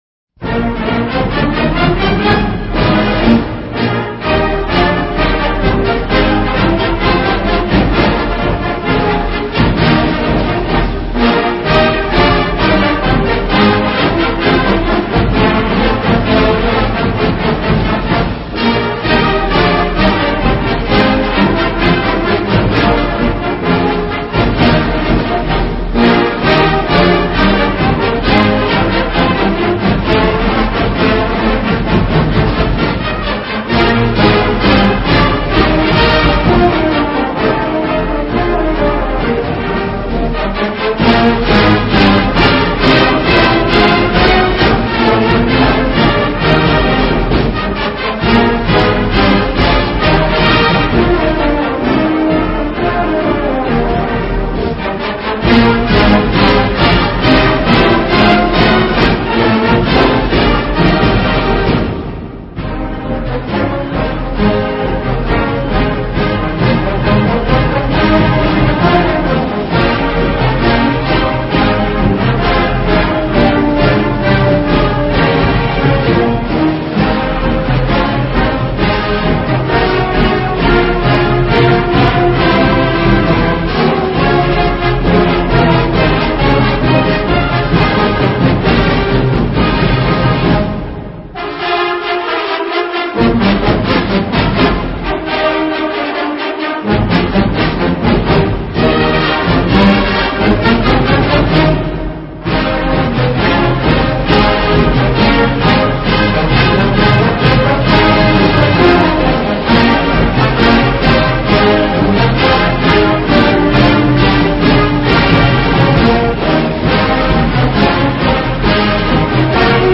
Since most theme music for colleges orginated in the 1920s and 30s, the sound is a traditional one with modernist tweaks: military band arrangements with ragtimey accent, typically played very quickly, often ripped through at lightspeed following big plays in games.
“On Wisconsin” is another classic of great dignity and grandeur, even if we always sing the following lyrics to it: